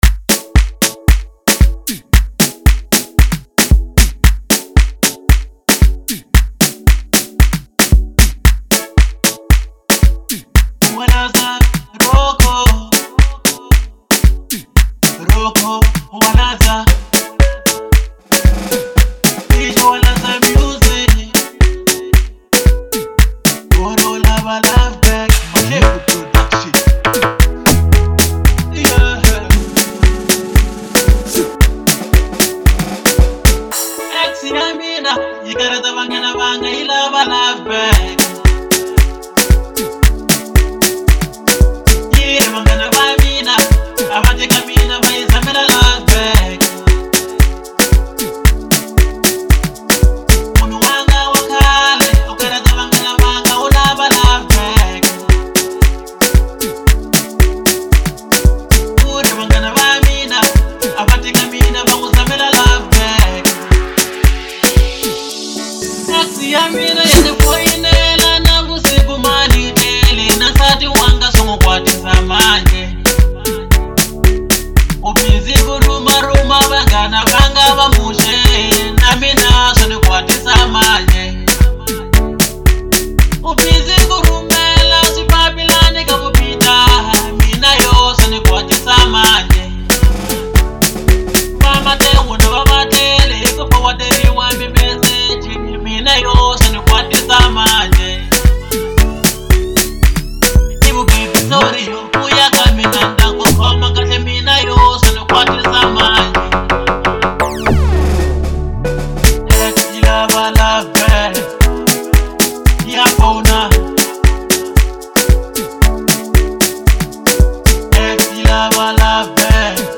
Local House